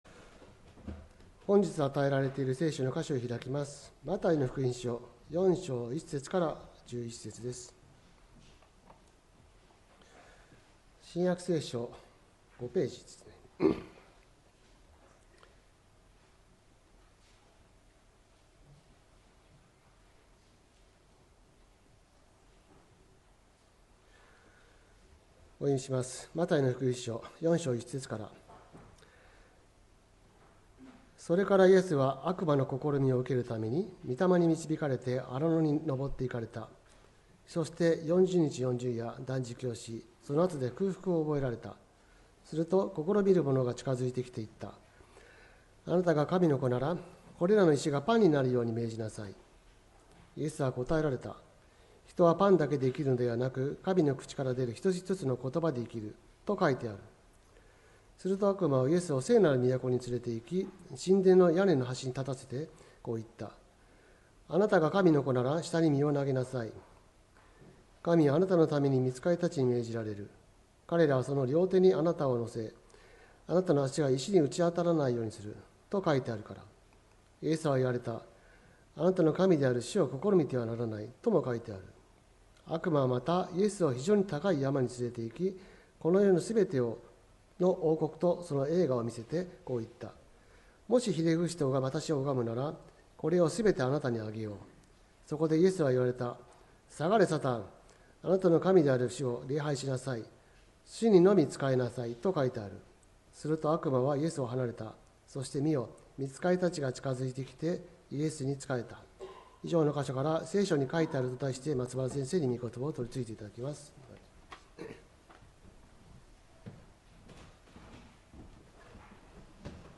礼拝メッセージ「聖書に、書いてある」(６月22日）